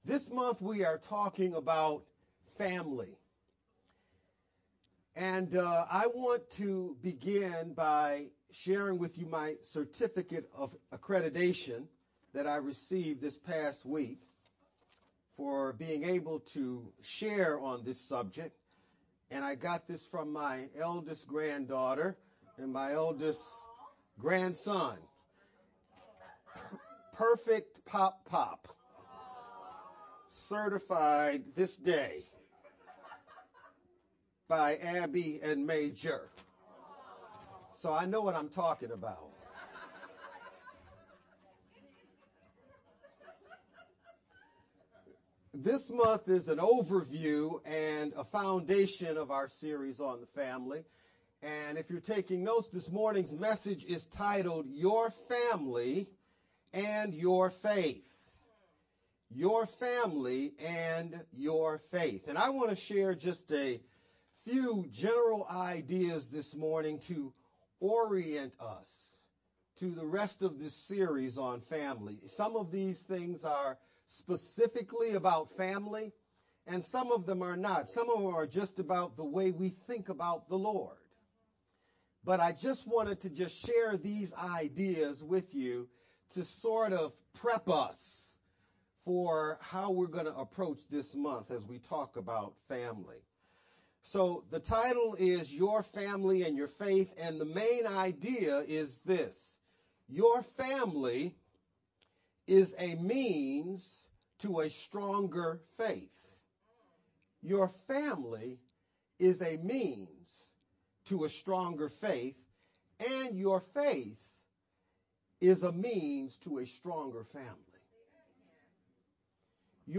June-6th-Sermon-only-Mp3-CD.mp3